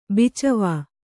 ♪ bicavā